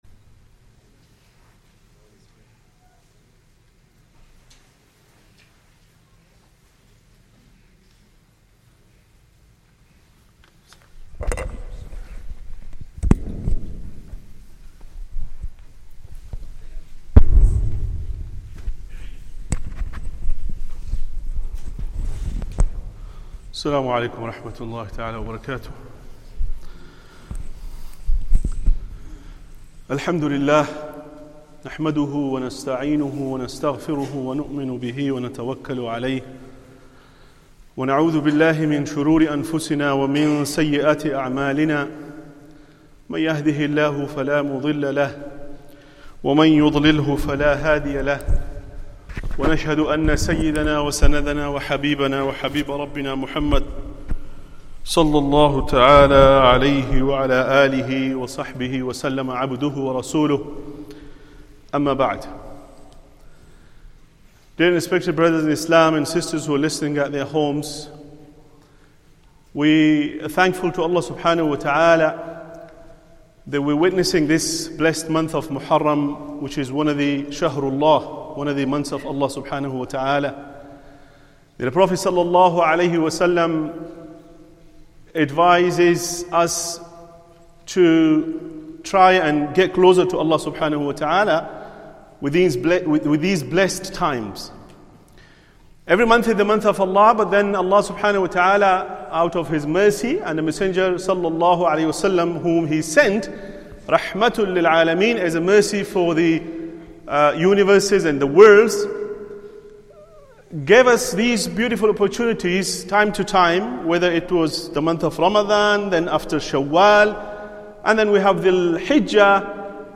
Masjid Al Farouq | Jumuah